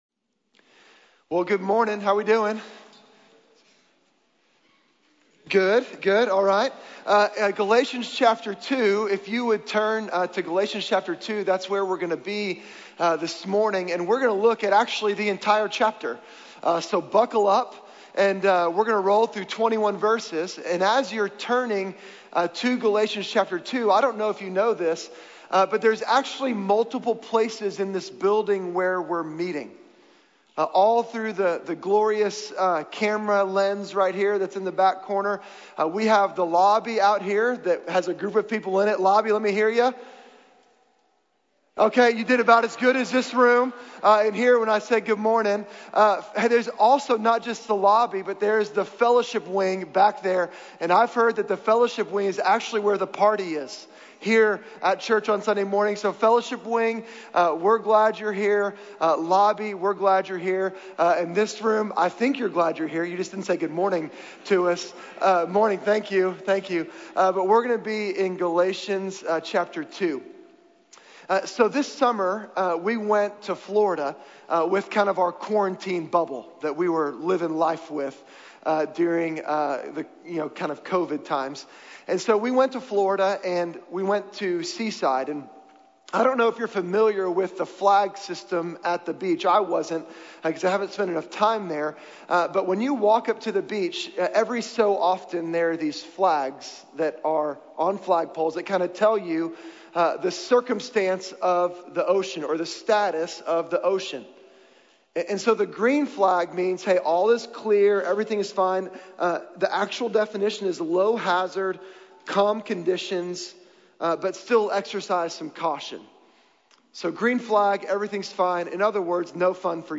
The Slow Drift | Sermon | Grace Bible Church